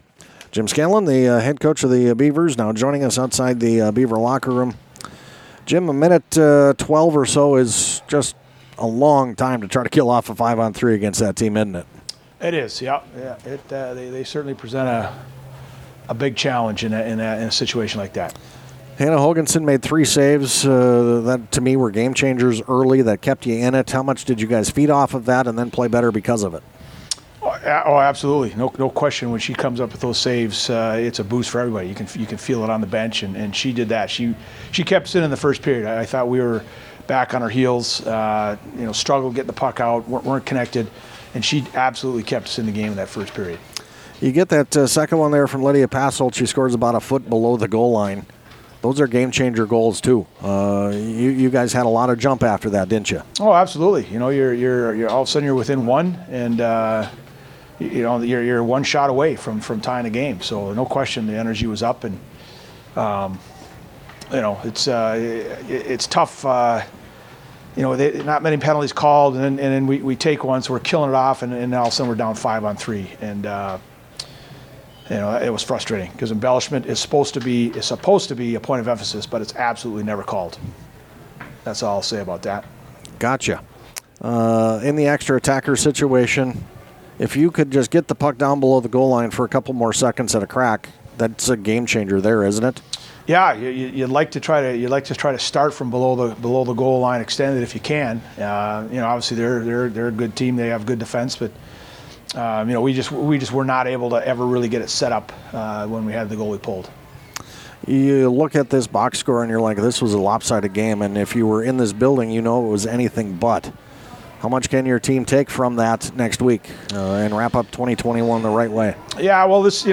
postgame.mp3